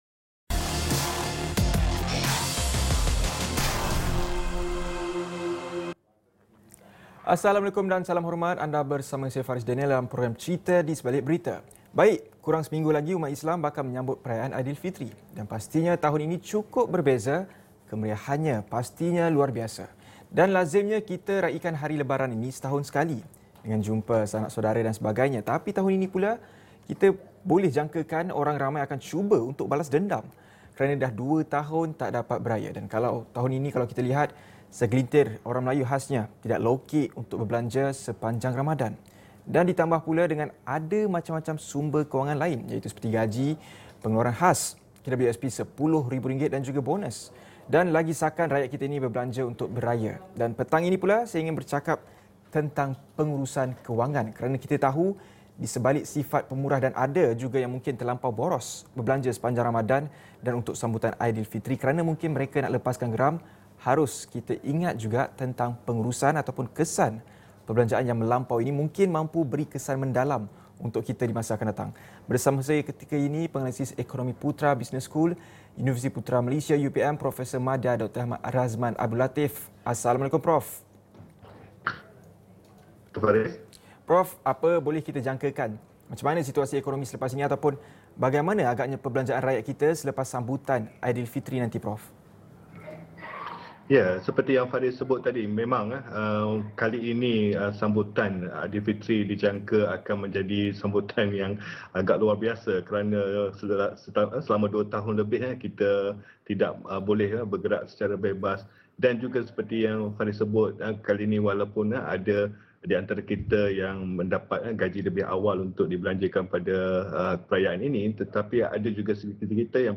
Wartawan Astro AWANI menceritakan kisah di sebalik sesuatu berita yang dilaporkan.